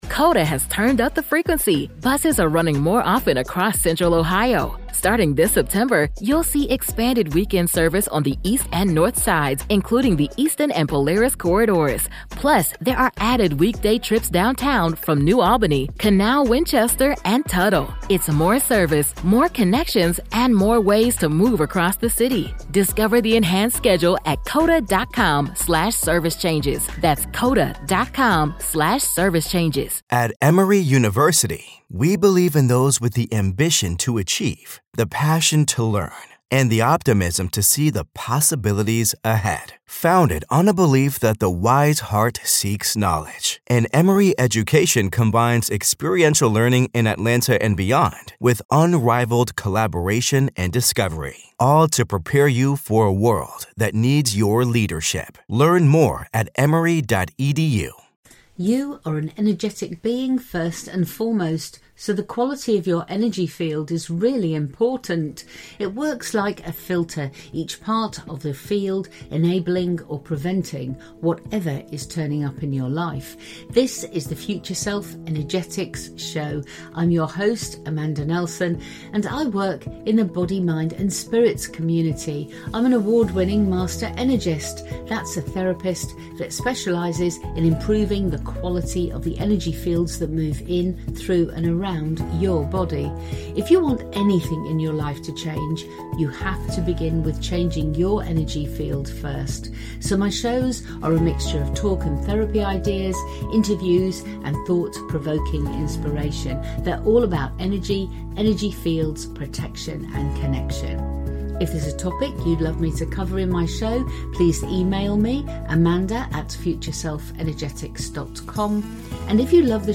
The Power to Heal with Scintillating Scalar Light. Interview